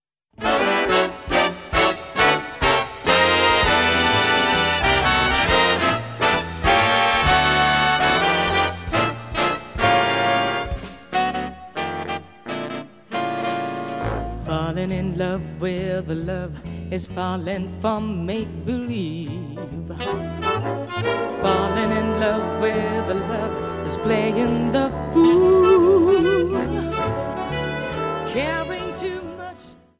voc